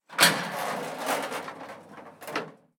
Abrir la puerta interior de un ascensor
ascensor
Sonidos: Hogar